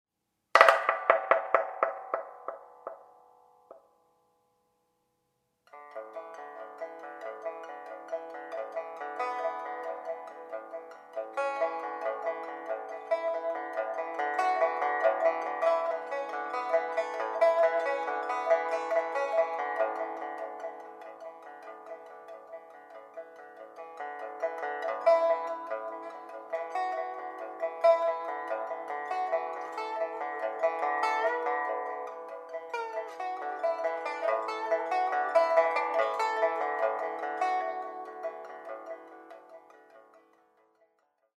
pipa